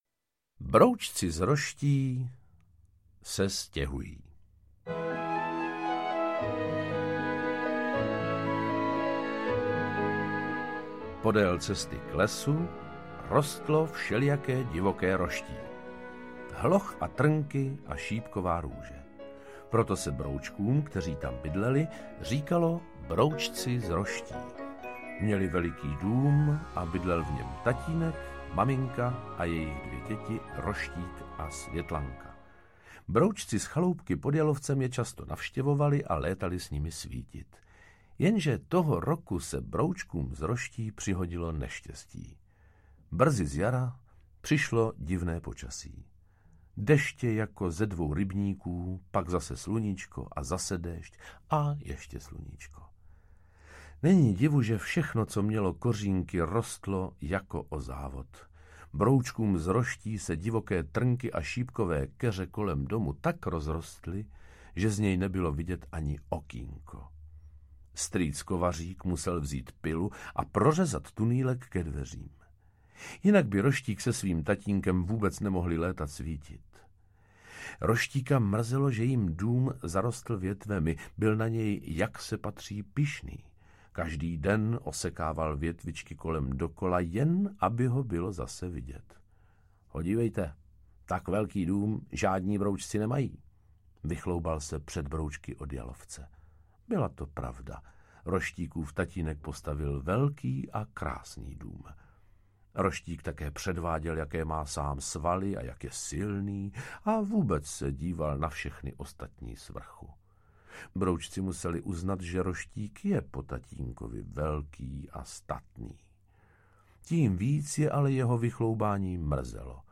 Ukázka z knihy
Půvabné vyprávění o životě broučků interpretuje Miroslav Donutil s okouzlujícím humorem a vřelostí.
broucci-3-brouckova-rodina-audiokniha